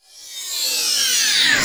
VEC3 Reverse FX
VEC3 FX Reverse 43.wav